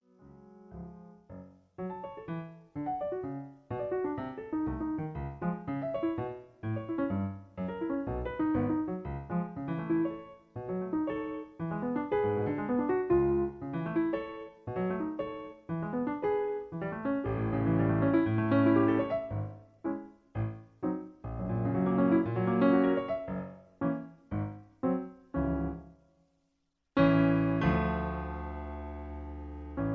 Piano accompaniment track